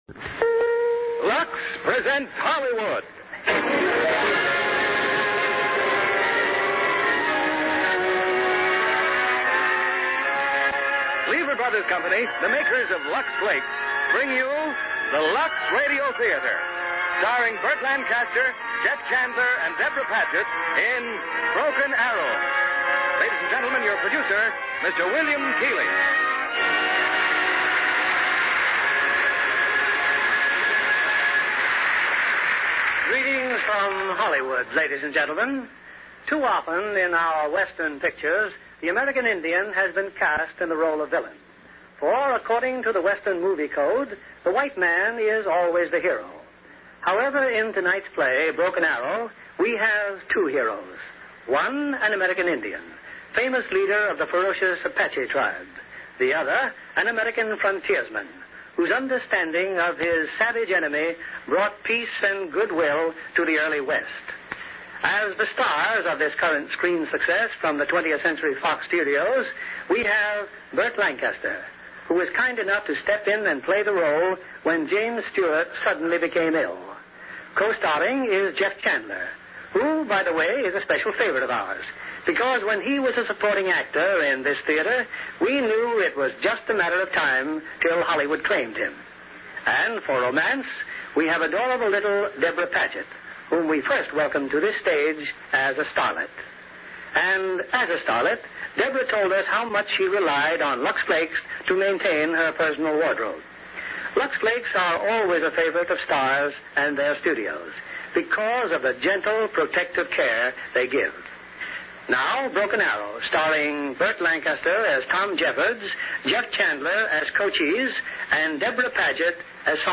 Broken Arrow, starring Burt Lancaster, Deborah Paget, William Conrad